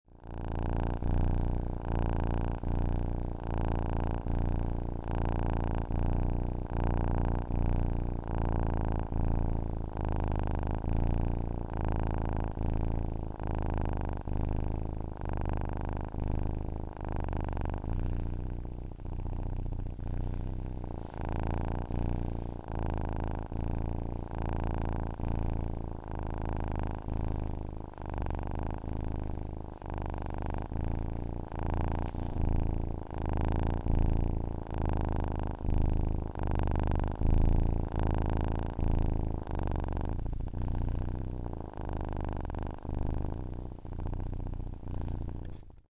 Cat Purring Sound Button - Free Download & Play